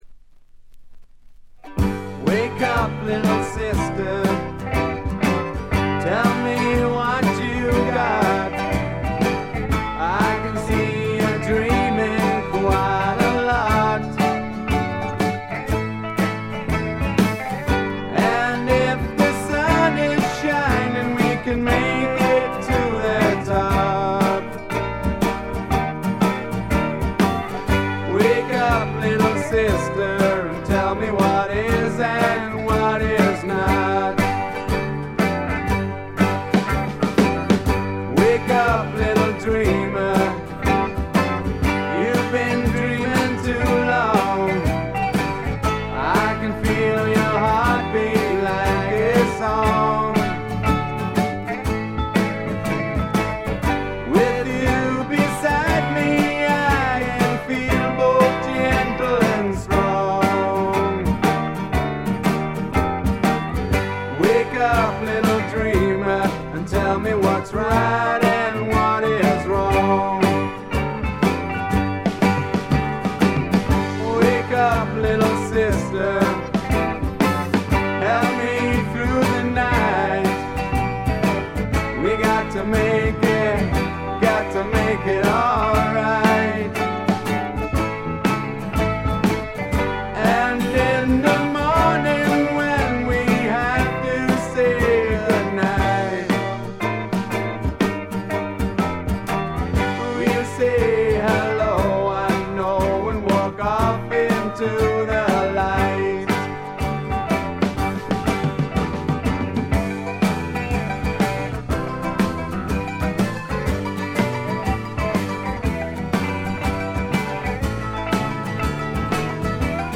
これぞ英国流フォークロックとも言うべき名作です。
試聴曲は現品からの取り込み音源です。